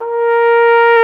Index of /90_sSampleCDs/Roland LCDP12 Solo Brass/BRS_Cornet/BRS_Cornet 2